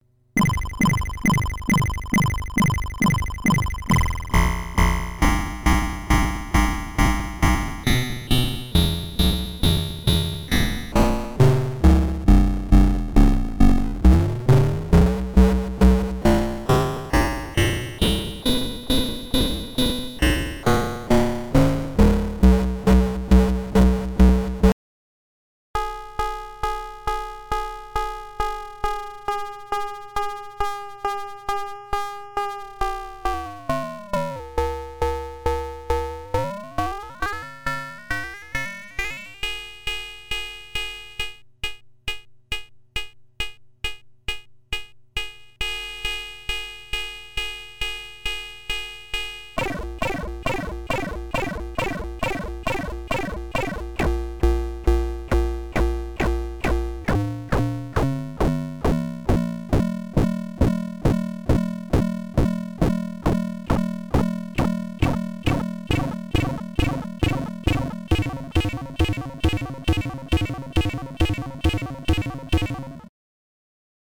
mbsidv2_drums_fx_examples.mp3